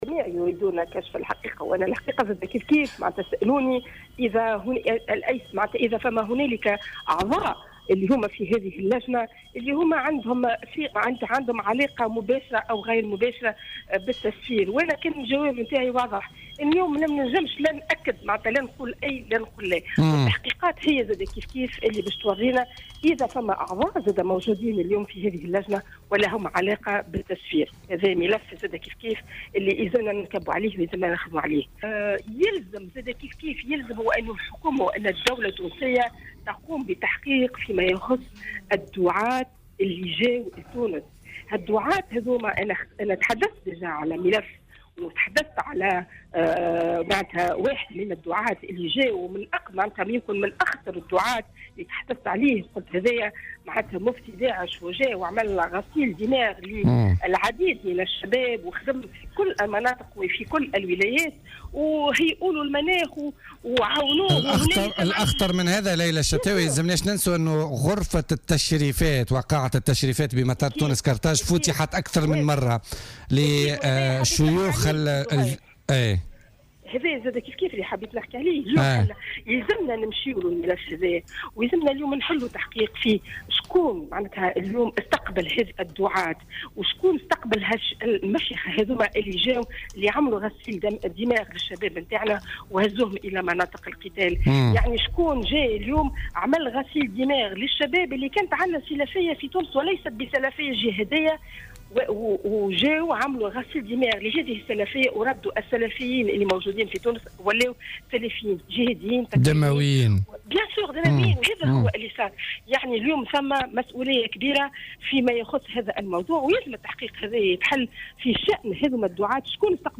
وأضافت في مداخلة لها اليوم في برنامج "بوليتيكا" أن عملية التسفير كانت منظمة و ضخّت من أجلها أموالا طائلة، مشيرة إلى أن هذا الملف صعب ومعقد، وفق تعبيرها.